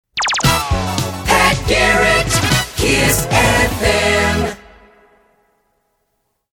Jock ID